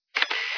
Maquina fotográfica
Sonido "clic" del pulsador accionando una cámara fotofráfica en la toma de una imagen.